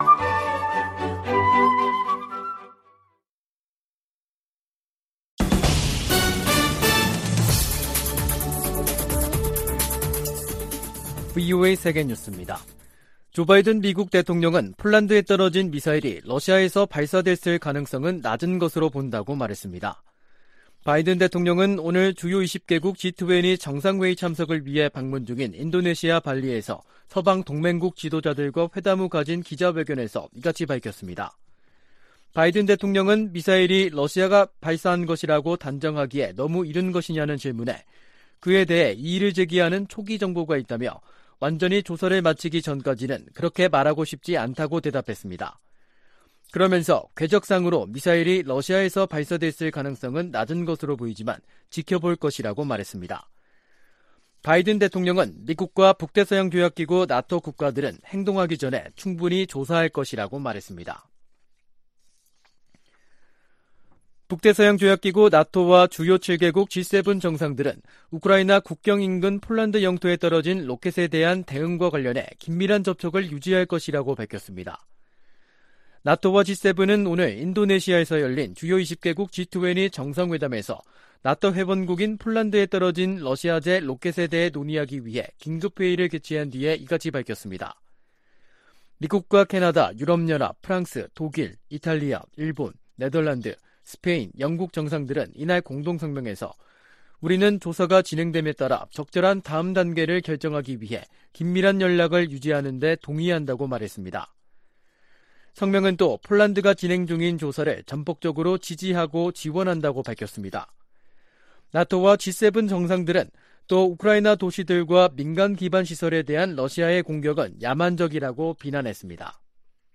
VOA 한국어 간판 뉴스 프로그램 '뉴스 투데이', 2022년 11월 16일 2부 방송입니다. 도널드 트럼프 전 미국 대통령이 2024년 대통령 선거에 다시 출마할 것이라고 공식 발표했습니다. 한국과 중국 두 나라 정상이 15일 열린 회담에서 북한 문제에 대해 논의했지만 해법을 놓고는 기존 시각차를 확인했을 뿐이라는 평가가 나오고 있습니다.